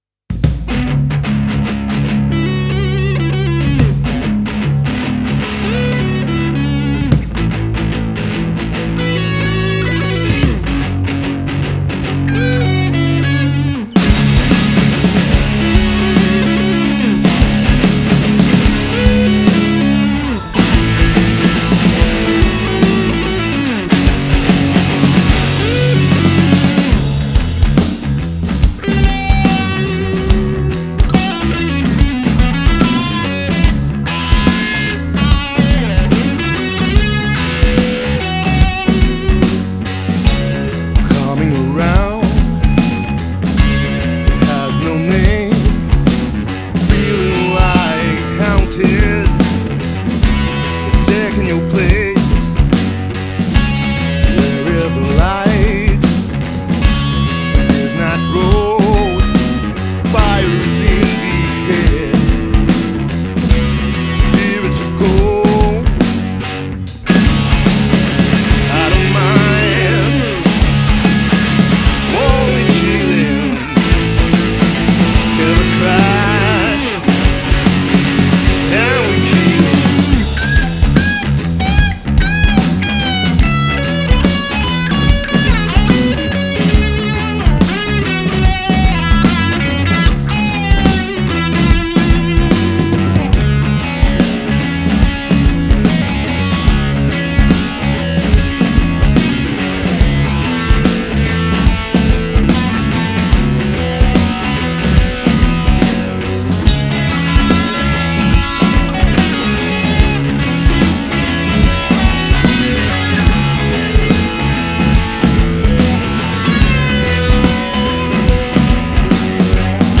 c’est la formation du duo batterie guitare